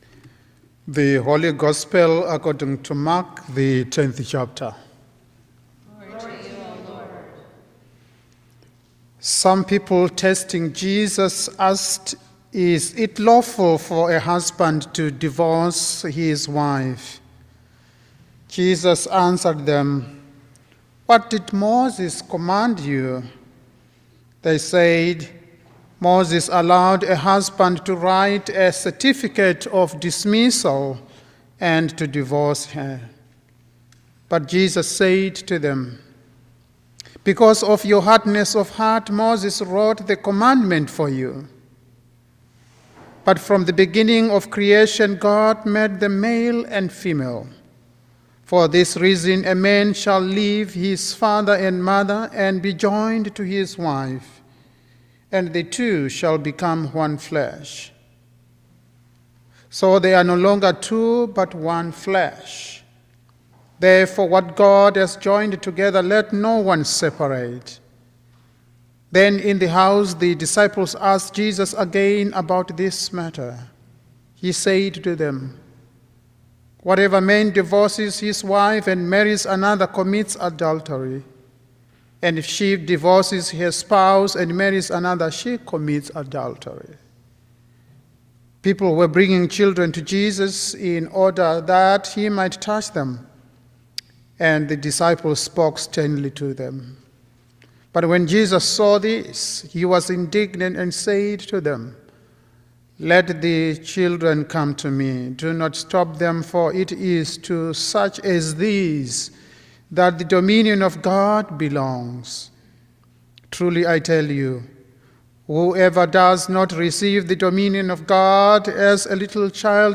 Sermon for the Twentieth Sunday after Pentecost 2024